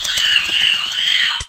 PixelPerfectionCE/assets/minecraft/sounds/mob/bat/death.ogg at ca8d4aeecf25d6a4cc299228cb4a1ef6ff41196e